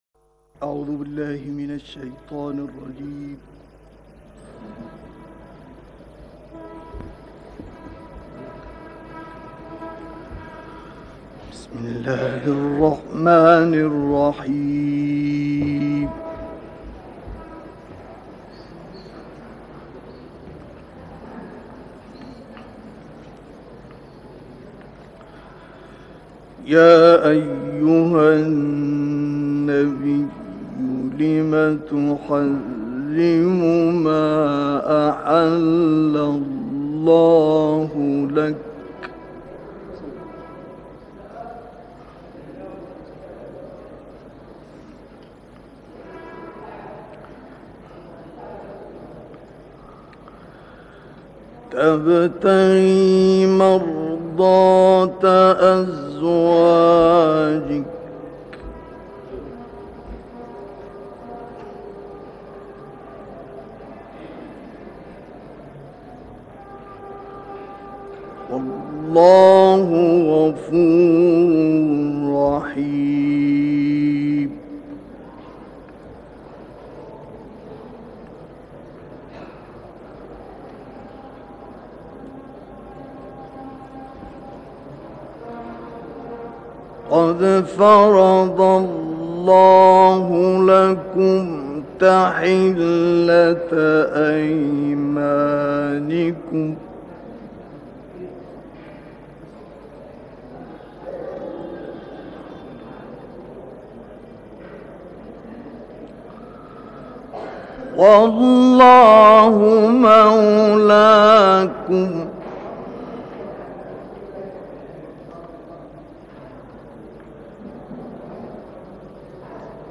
صوت/ تلاوتی از دوران جوانی اکبرالقراء
گروه چندرسانه‌ای ــ تلاوت سوره‌های مبارکه تحریم و حاقه با صوت مصطفی اسماعیل، قاری بنام جهان اسلام منتشر شد. این تلاوت ۴۸ دقیقه‌ای در سال ۱۹۵۴ میلادی در مسجد امام حسین (ع) شهر قاهره اجرا شده است.